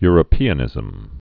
(yrə-pēə-nĭzəm)